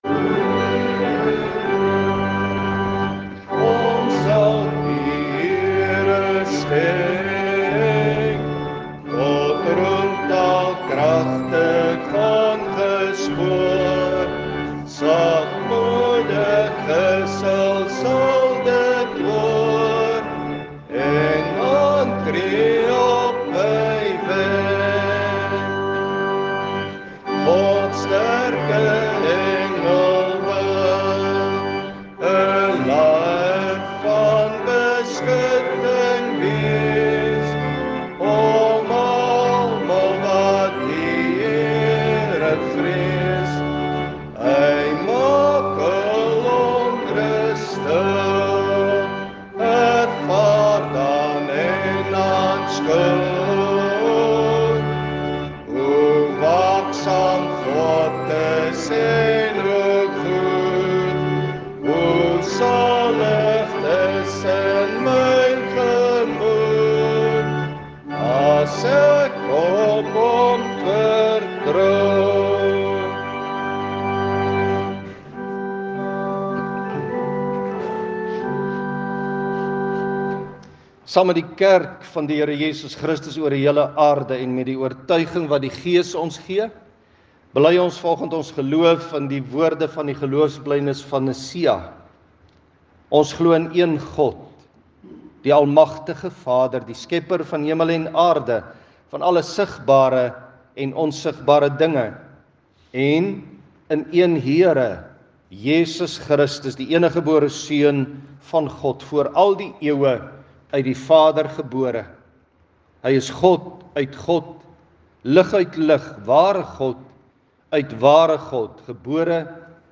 Klankbaan Luister na die preek.